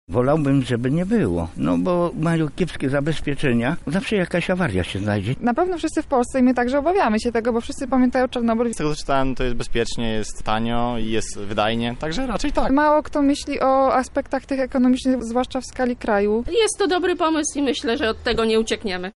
O zdanie zapytaliśmy lublinian.
sonda